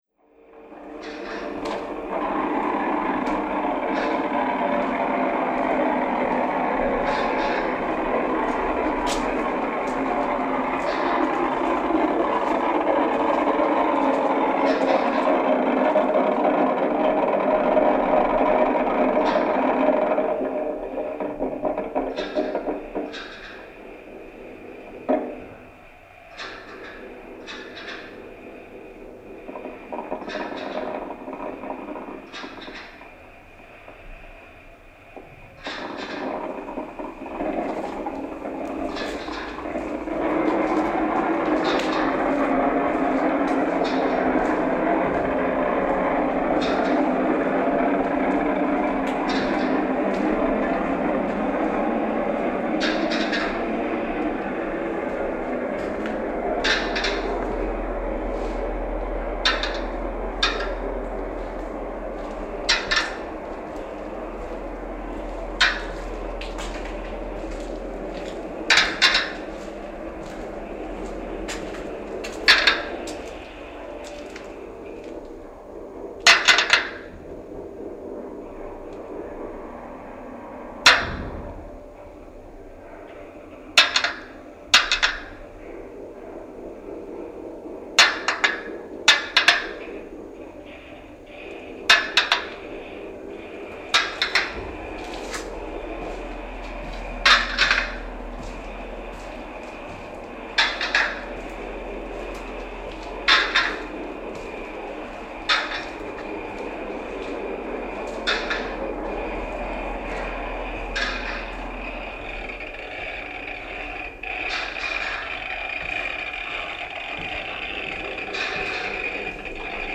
Here is a 3m50s audio recording (in stereo if you have headphones) which follows the sounds as I walk into each of the aisles as described above:
It is an underground, crypt-like space, with damp walls and good acoustics, and is ironically also used to store the unfired ceramics from the art classes on the floor above.
The first aisle contained a loop of water dripping directly onto a contact mic, recorded in an art studio upstairs. The second was a loop of shells being dropped onto a metal target. The third was the sound of a contact mic being dragged along the path of a fired bullet to the end of the aisle. The fourth aisle contained a recording of the sculptors in the room above, thumping and hammering. Together, the piece sounded like gunfire and drums.
walking-into-four-aisles.mp3